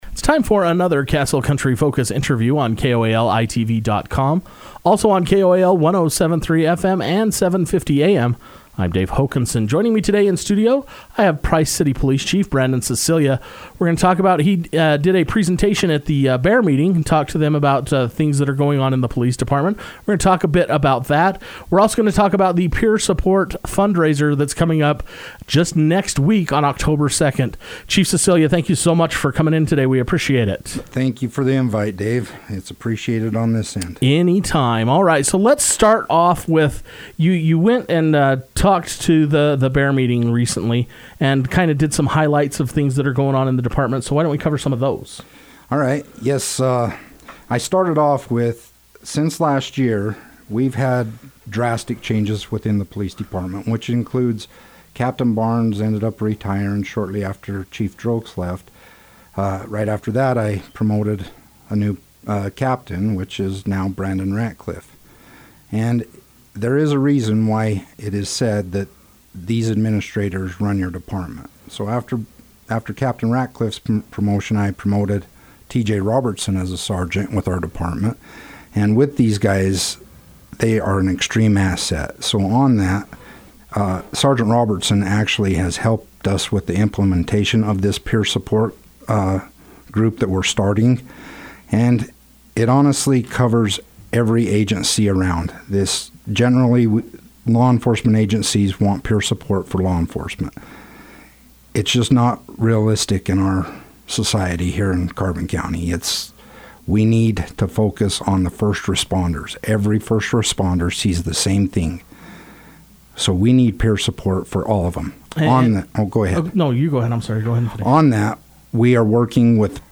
Castle Country Radio took time to sit down with Price City Police Chief Brandon Sicilia to discuss the upcoming Peer Support Fundraiser and other news taking place at the department.